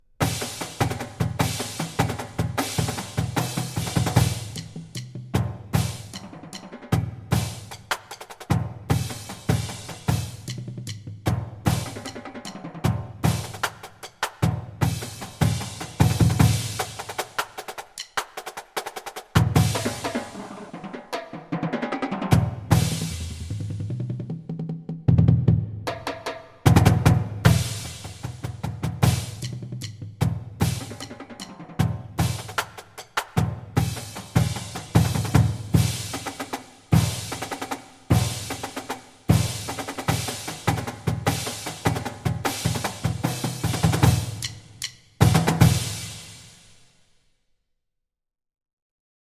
Voicing: Marching Percussion